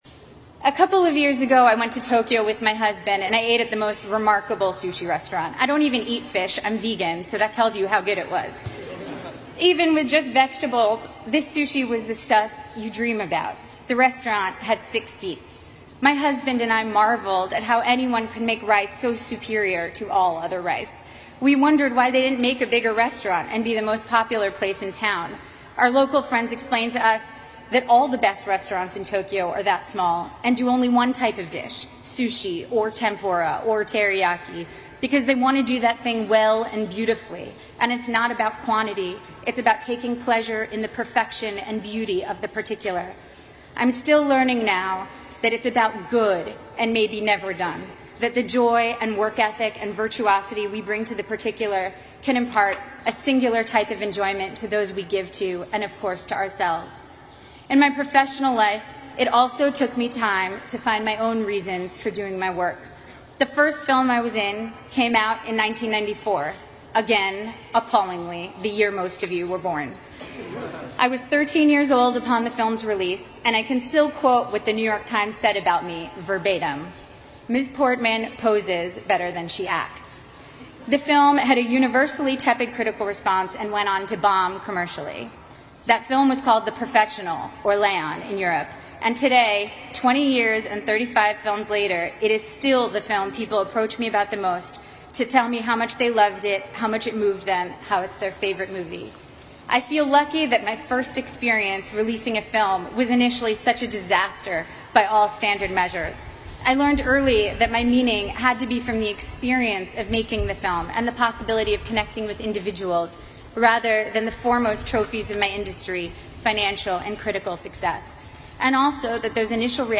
娜塔莉波特曼哈佛大学演讲 第6期 听力文件下载—在线英语听力室